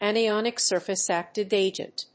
anionic+surface+active+agent.mp3